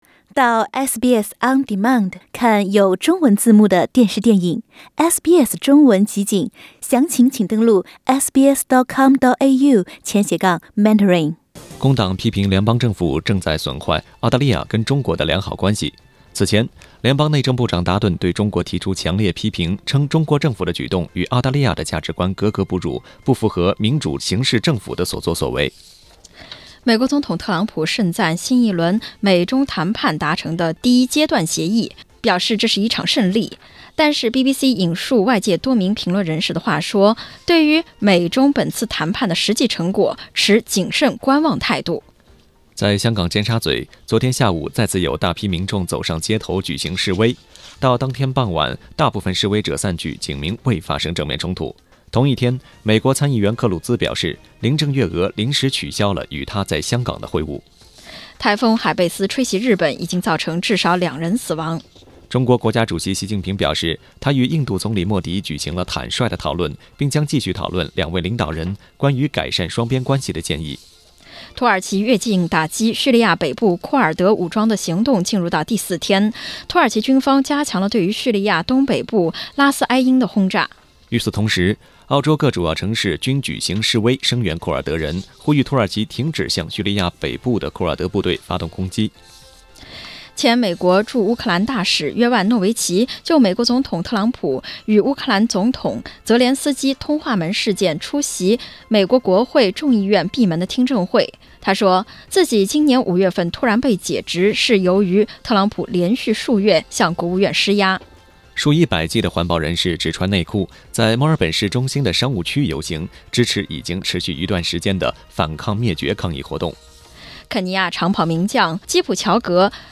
SBS早新闻（10月13日）